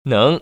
[néng]